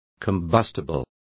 Complete with pronunciation of the translation of combustibles.